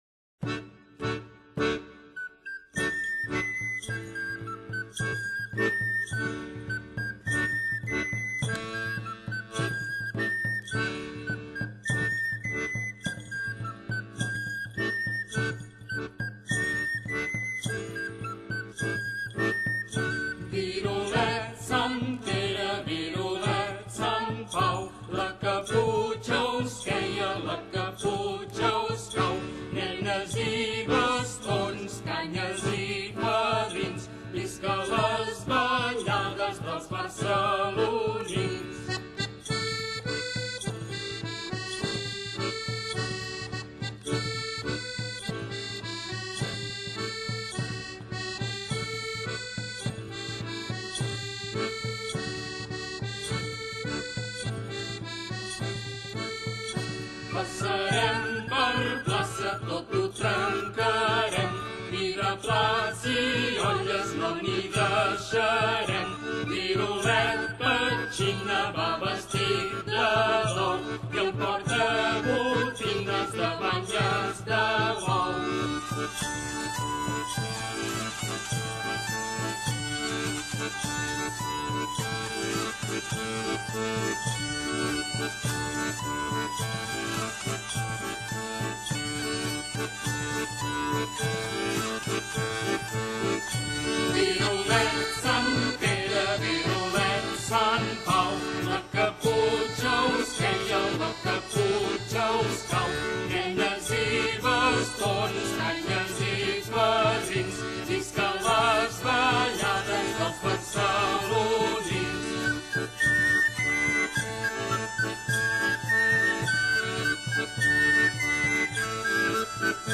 Nadal - Danses
Tradicional catalana